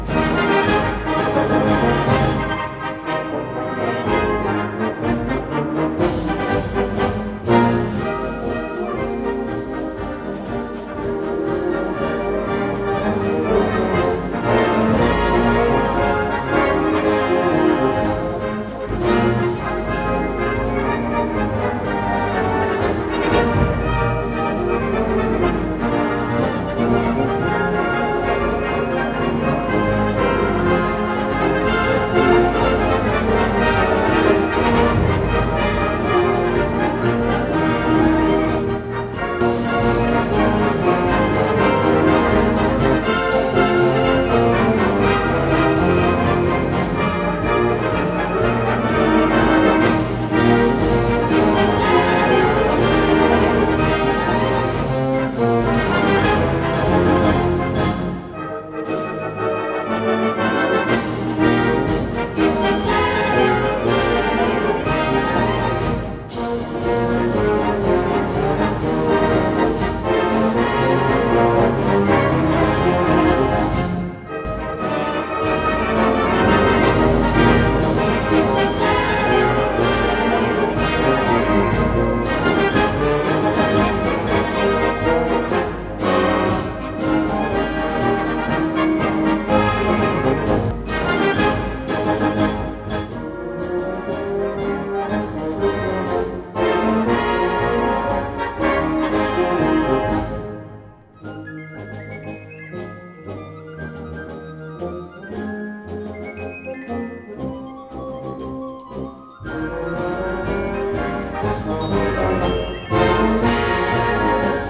für Harmonie